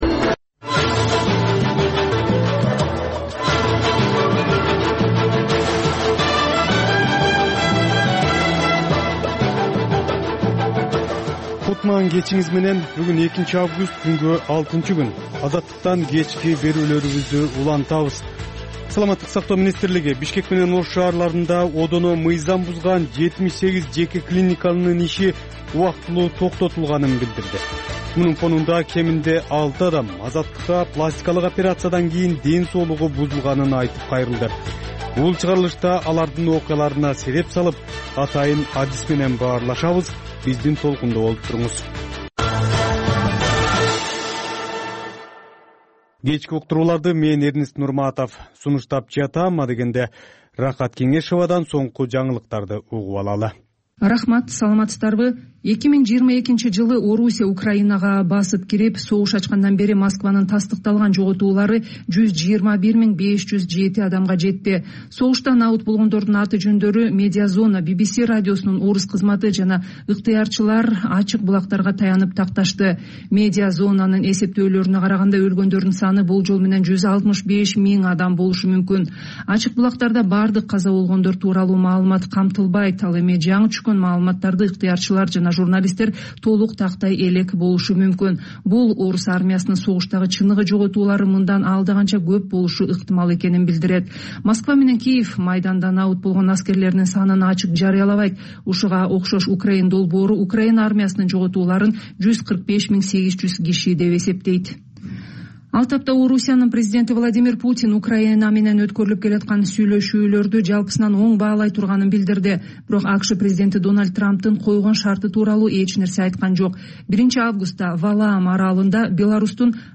Бул үналгы берүү ар күнү Бишкек убакыты боюнча саат 18:30ден 19:00га чейин обого түз чыгат.